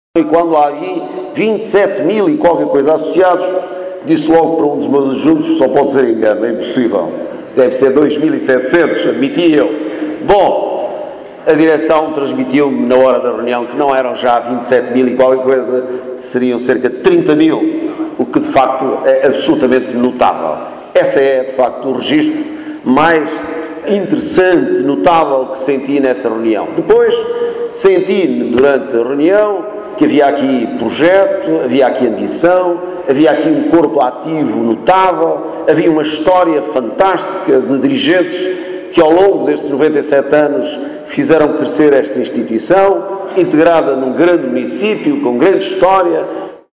A presidir à sessão solene, esta tarde, o secretário de Estado da Protecção Civil, Artur Tavares Neves, destacou a vitalidade da corporação barcelinense.
Declarações do secretário de Estado da Protecção Civil, Artur Tavares Neves, que presidiu hoje à sessão solene, no âmbito das comemorações do 97.º aniversário dos Bombeiros Voluntários de Barcelos.